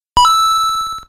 power-up.mp3